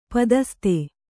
♪ padaste